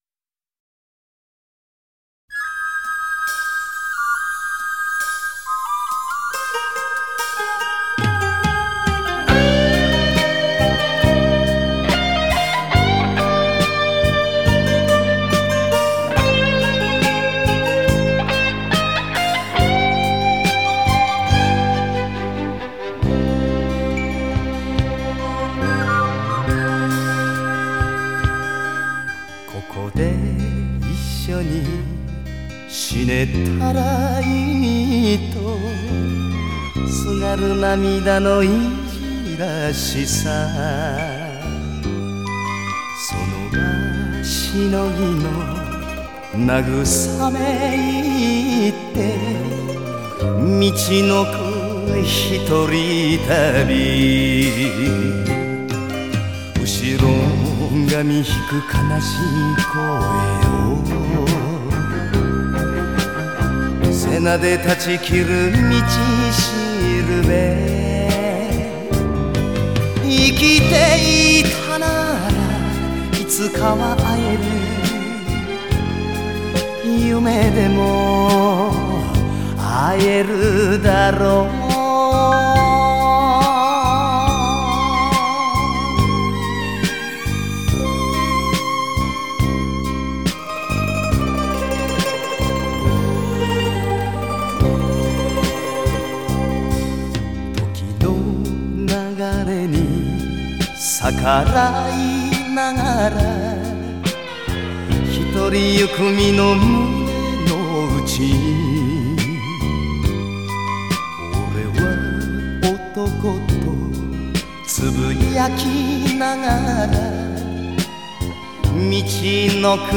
是日本著名的演歌歌手、演员。